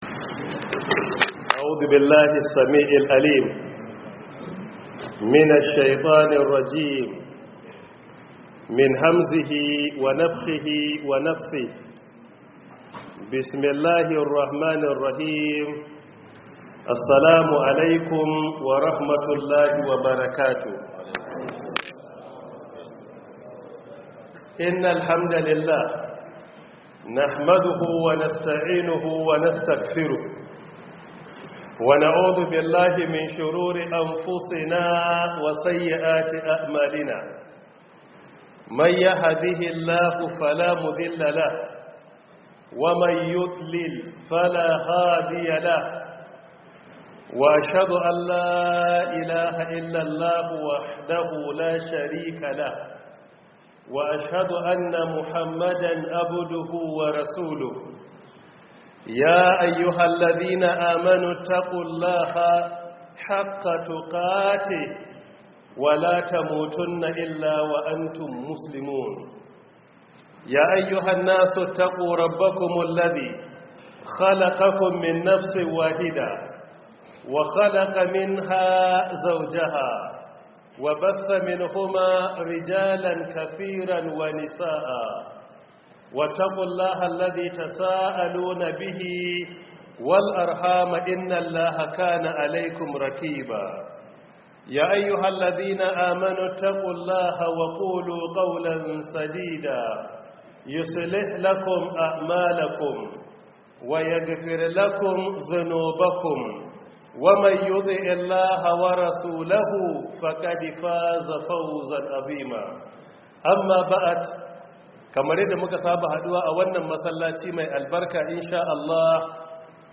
Audio lecture by Professor Isa Ali Ibrahim Pantami — 1447/2026 Ramadan Tafsir